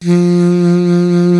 55AF-SAX02-F.wav